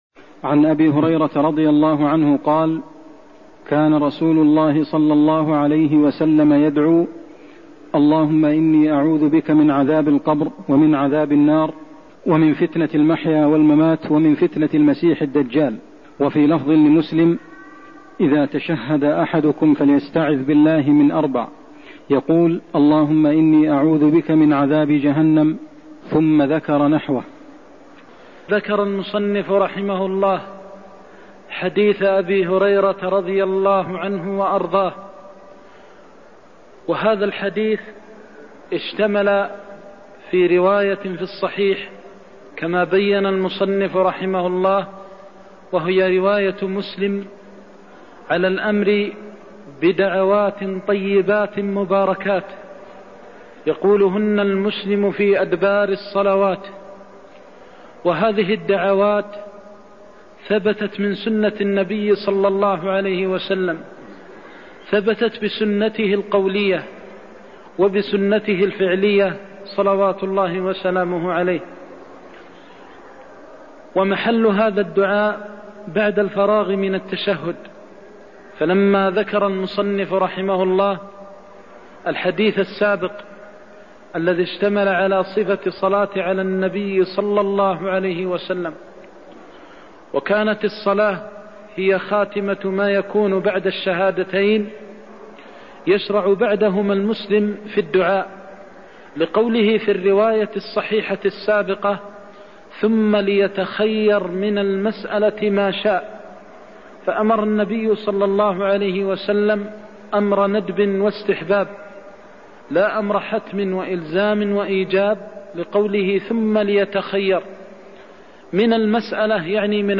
المكان: المسجد النبوي الشيخ: فضيلة الشيخ د. محمد بن محمد المختار فضيلة الشيخ د. محمد بن محمد المختار الدعاء بعد الفراغ من التشهد (116)